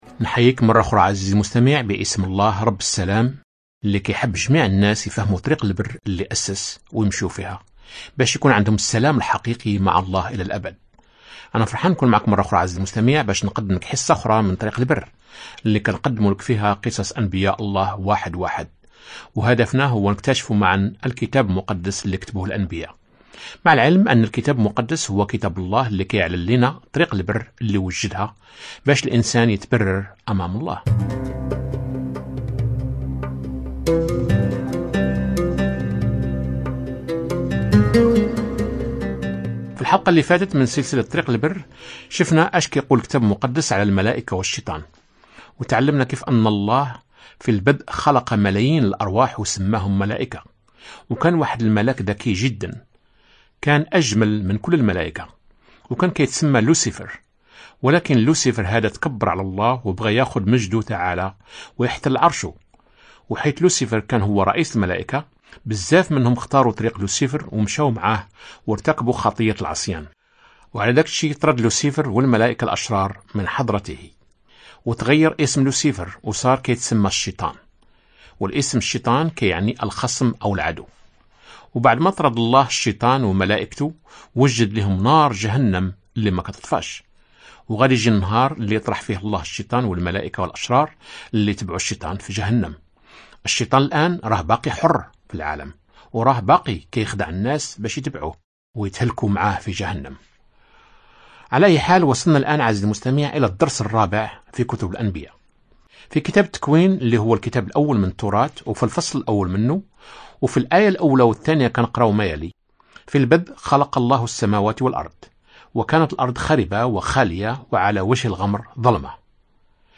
طريق البر باللهجة المغربية كيف خلص الله العالم؟